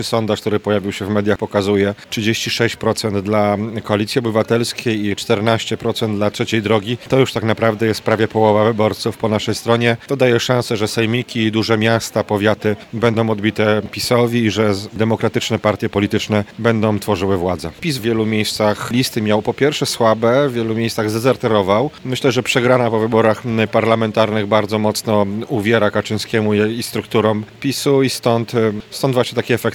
Stargardzkie struktury Koalicji Obywatelskiej podsumowały kończącą się dzisiaj kampanię do wyborów samorządowych. O najnowszych sondażach poparcia dla kandydatów z poszczególnych partii politycznych, mówi poseł Koalicji Obywatelskiej Grzegorz Napieralski.